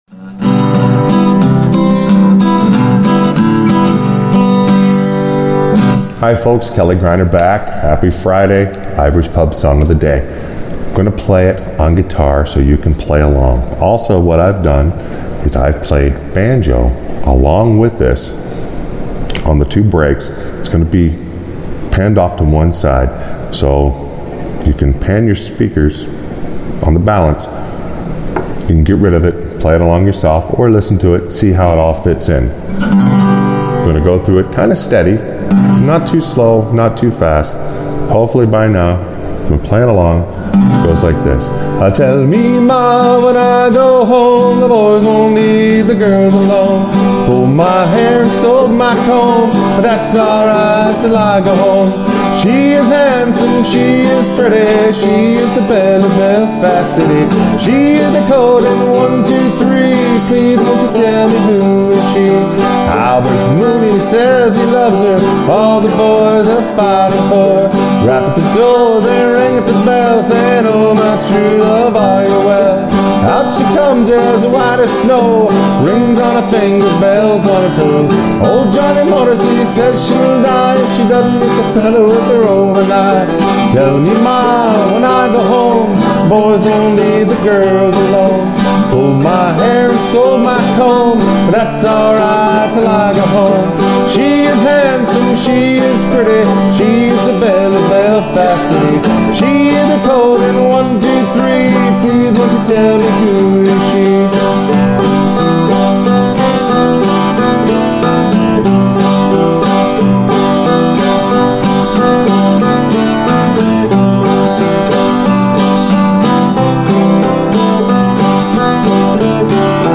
I am hoping that with this video you will be able to play along on your banjo while I play and sing with the guitar.